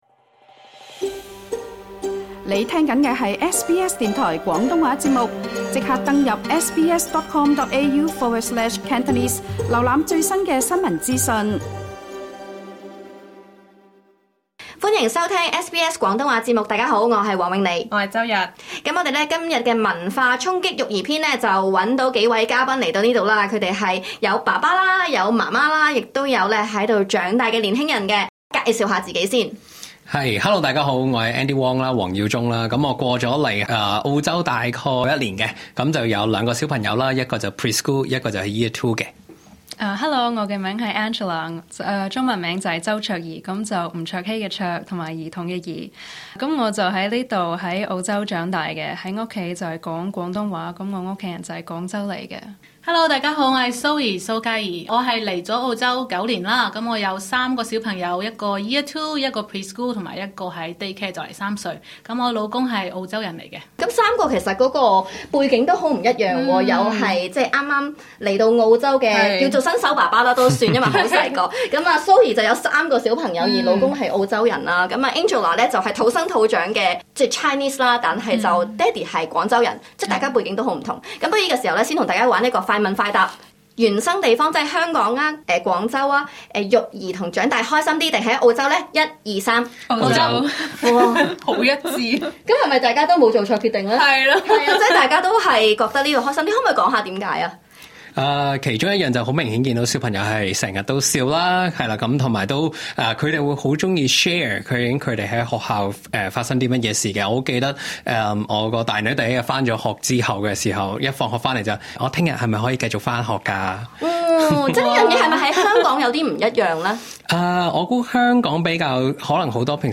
SBS廣東話邀請了三位不同背景及年齡人士，包括育有兩位年幼女兒的新移民爸爸、另一半是澳洲人兼育有三位小朋友的媽媽，以及於澳洲土生土長的年輕華人，一同分享澳洲育兒及成長的種種文化差異及趣事。